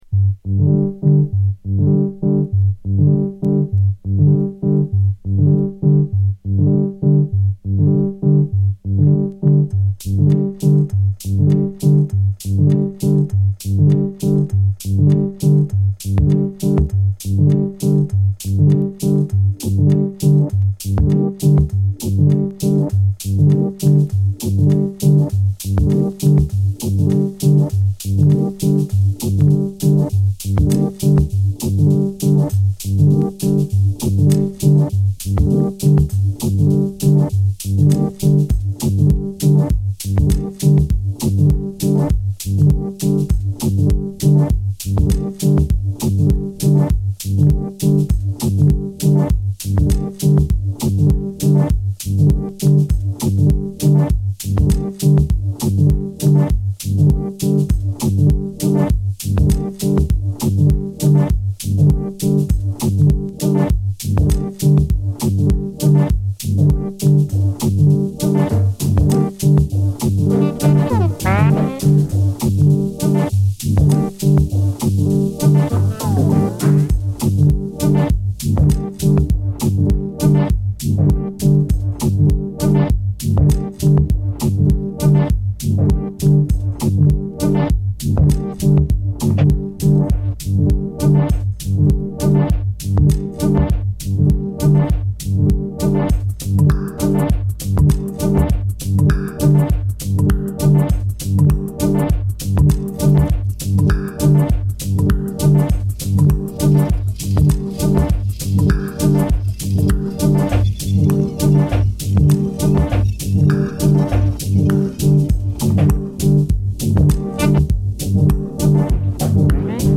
ある意味異色のヒップホップ・ビート・アルバムの様な魅力も放つ作品。